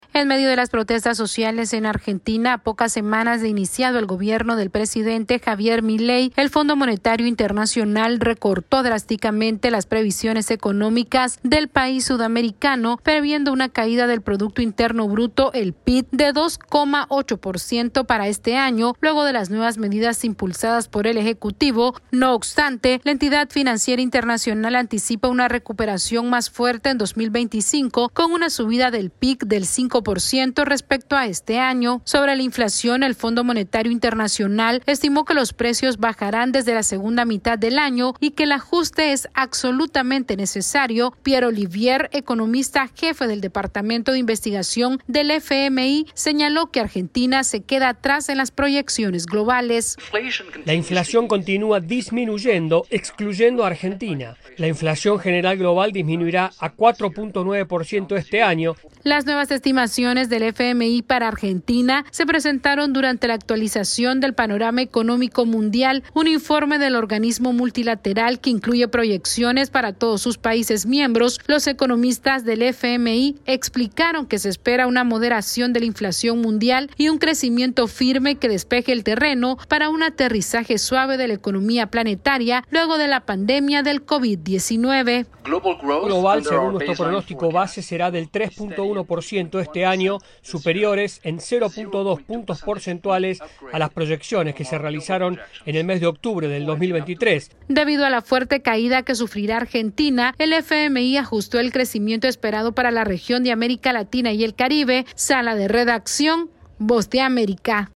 El Fondo Monetario Internacional redujo drásticamente las perspectivas de crecimiento económico de Argentina en medio del descontento popular por los ajustes del gobierno de Javier Milei. Esta es una actualización de nuestra Sala de Redacción...